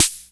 Vintage Snare 02.wav